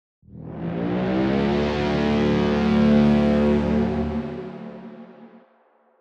Braam Sfx Sound Intro